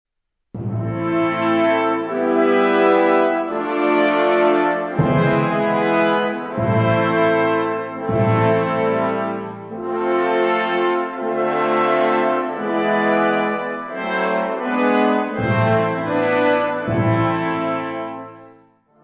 Instrumentation Ha (orchestre d'harmonie)